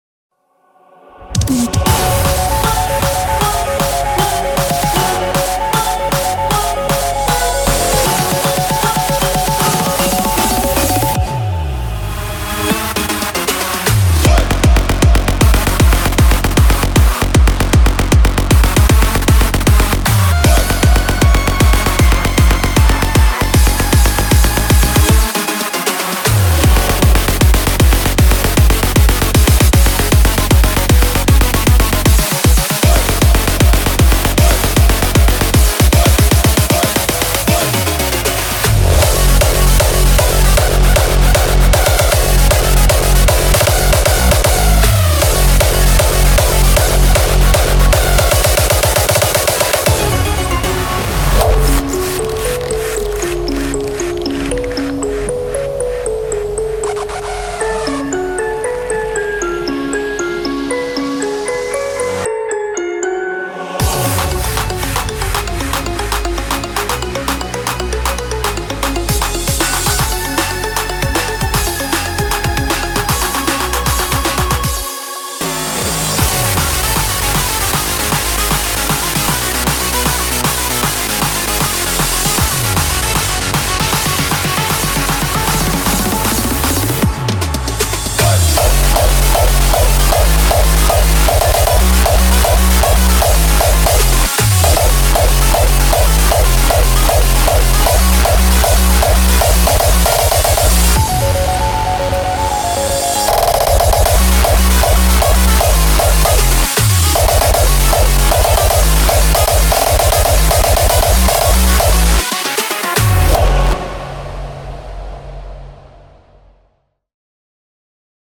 BPM155
Audio QualityPerfect (Low Quality)